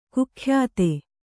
♪ kukhyāte